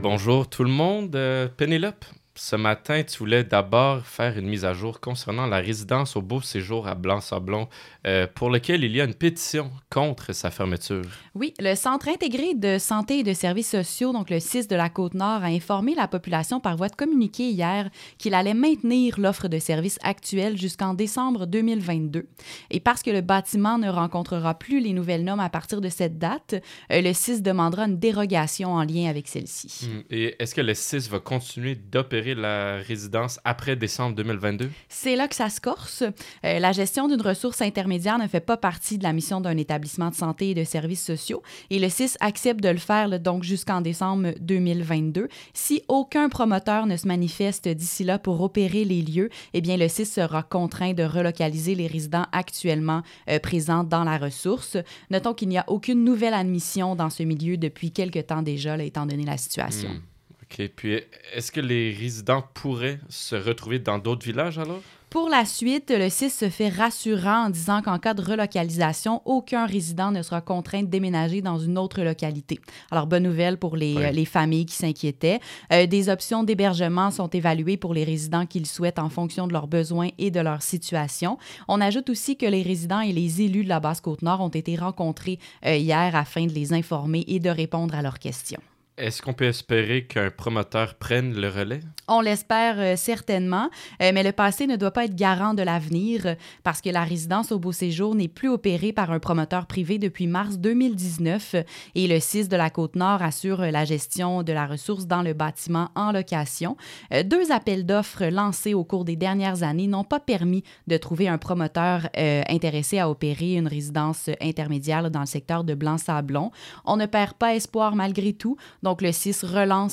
Aux-Beaux-Séjours-segment-radio-2.mp3